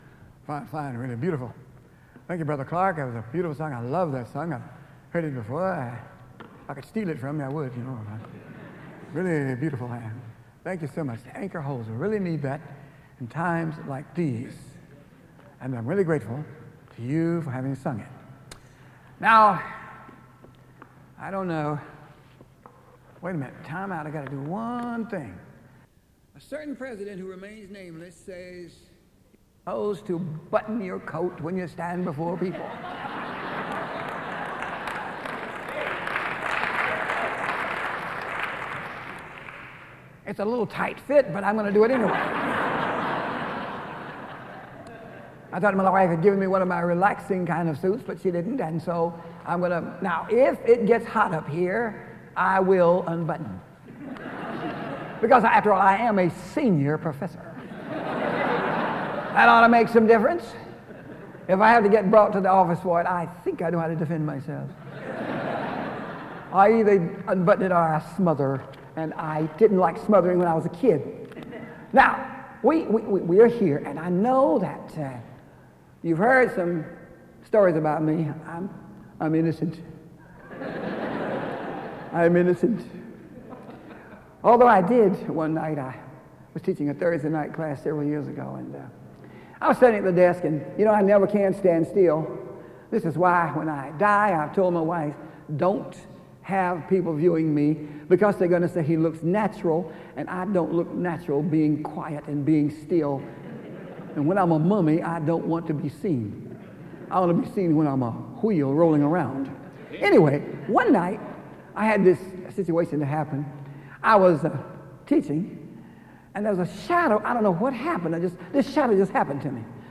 SEBTS Chapel
Good Friday sermons